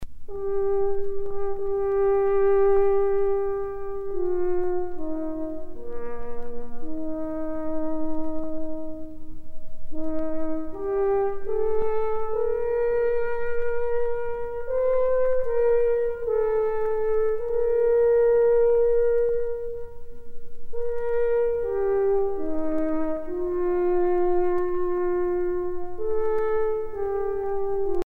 trompe - Fanfares et fantaisies de concert
circonstance : vénerie